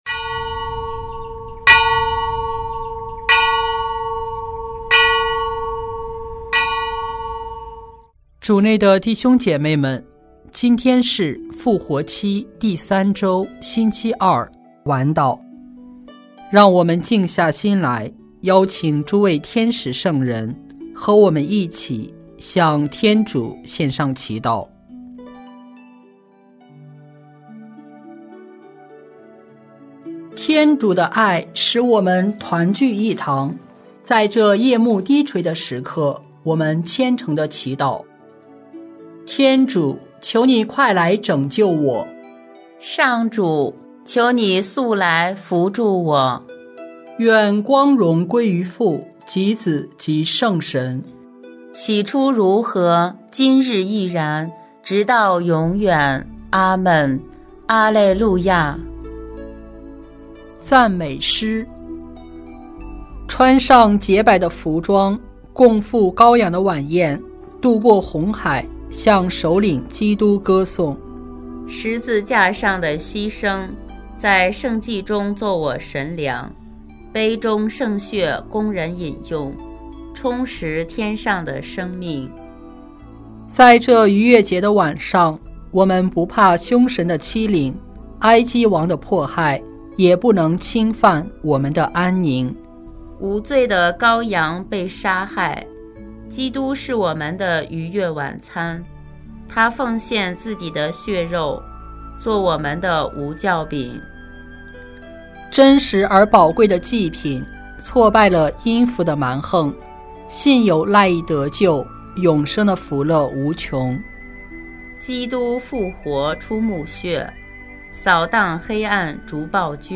4月21日复活期第三周星期二晚祷